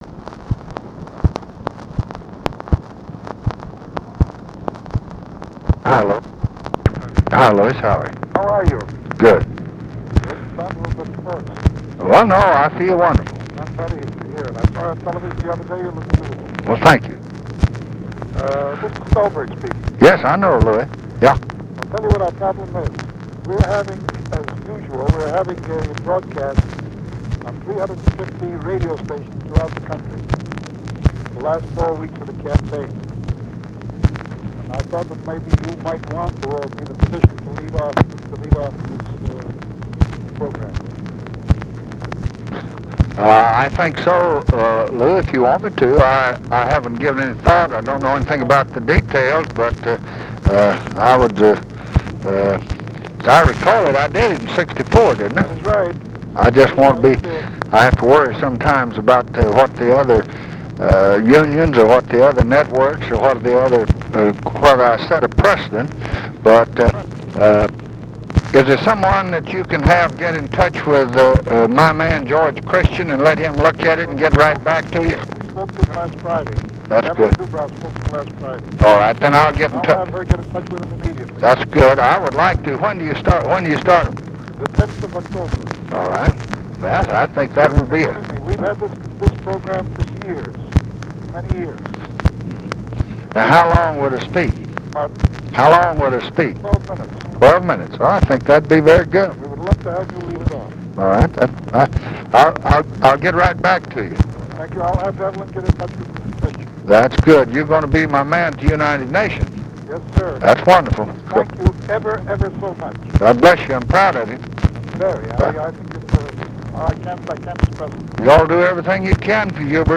Conversation with LOUIS STULBERG, September 30, 1968